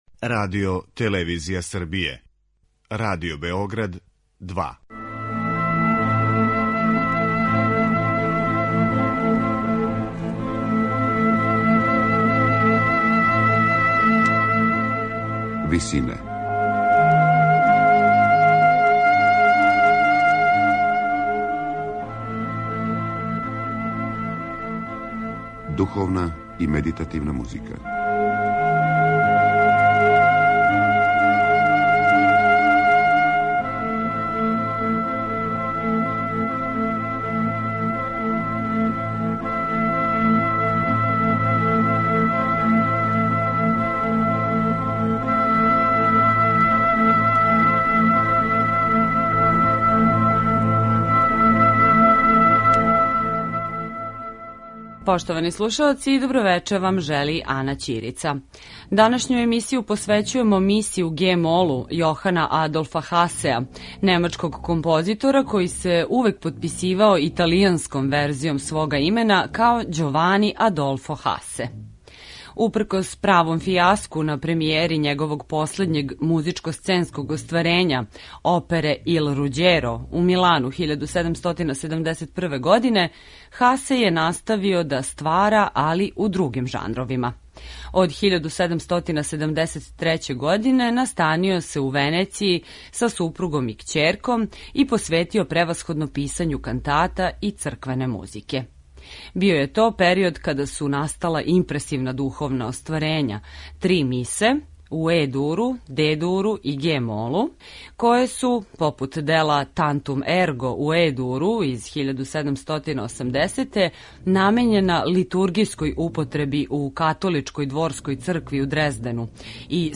Хасеово последње остварење написано је за четворо солиста, хор и оркестар, укључујући трубе и тимпане, што је представљало огроман извођачки апарат за то време.
На крају програма, у ВИСИНАМА представљамо медитативне и духовне композиције аутора свих конфесија и епоха.